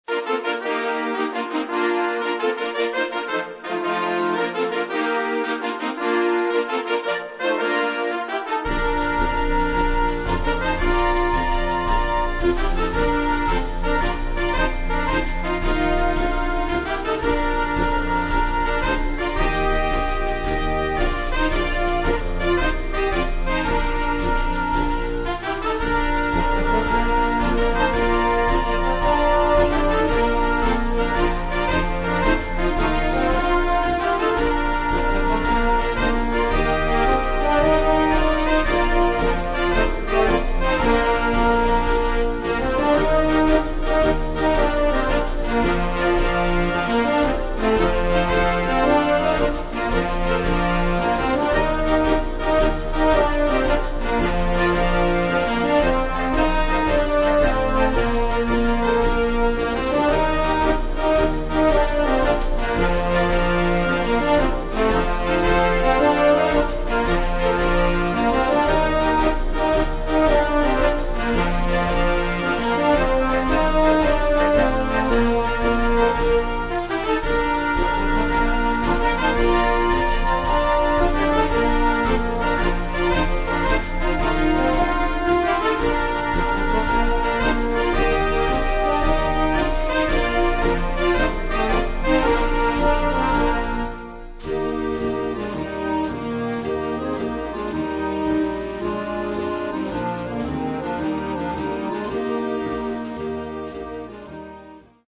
Danses